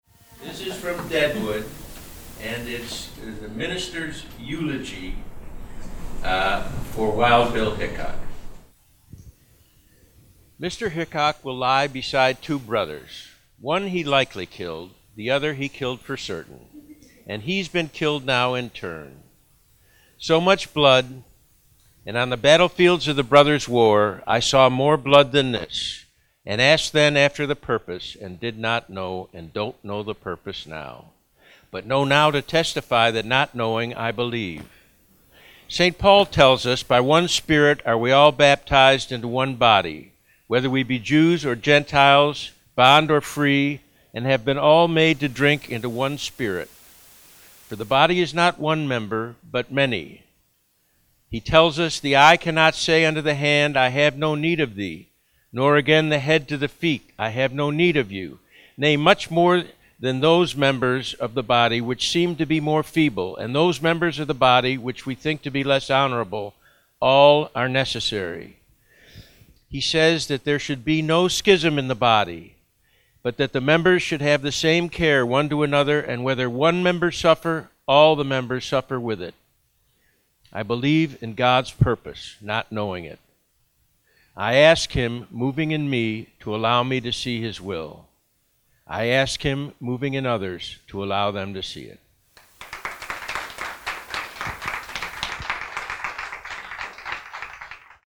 Spoken Word
Read by David S. Milch Deadwood : Season 1, Episode 5 The Trial of Jack McCall